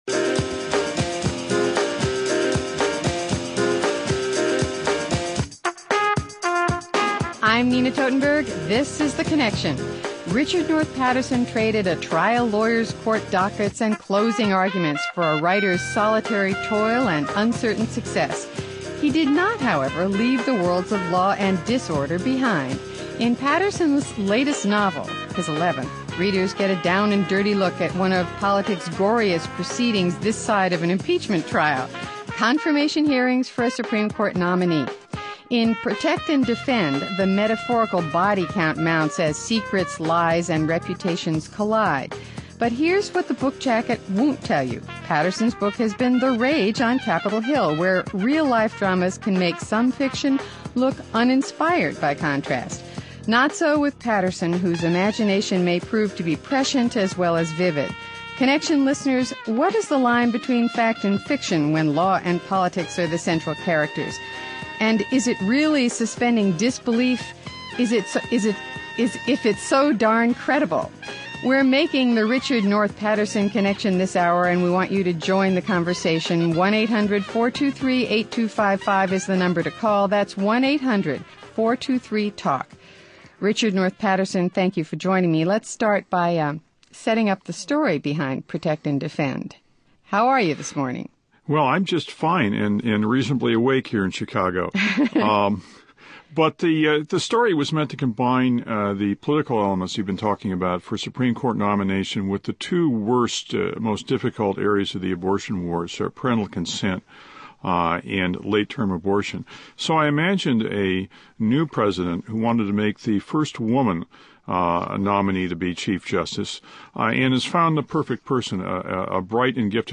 You also get the latest novel by best-selling author Richard North Patterson. Law, politics, and the challenges of creating fiction where the reality is already so strange, here. (Hosted by Nina Totenberg)